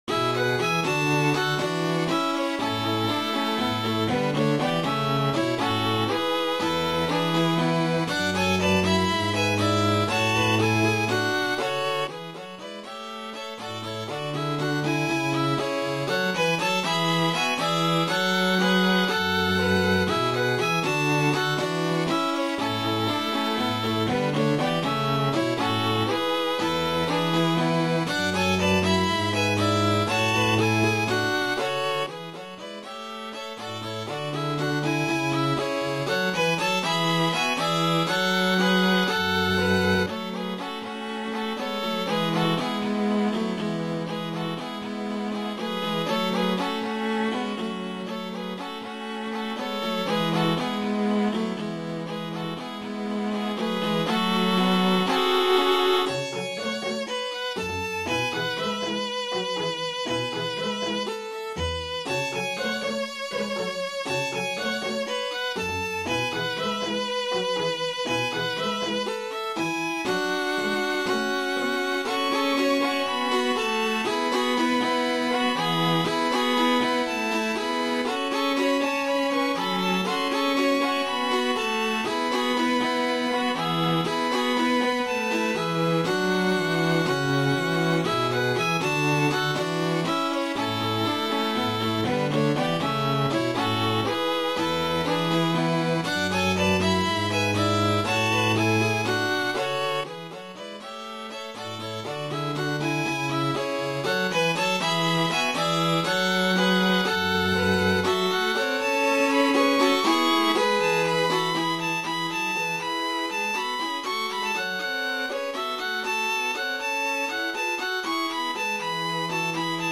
Das MP3 wurde vom Computer erstellt.